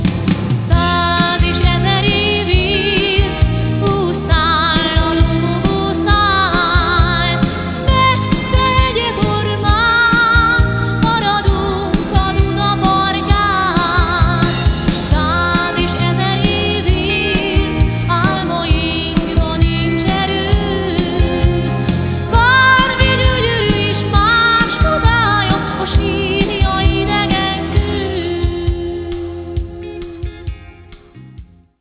ének
gitár, billentyűs hangszerek, szájharmonika, vokál
bassz, bongó, vokál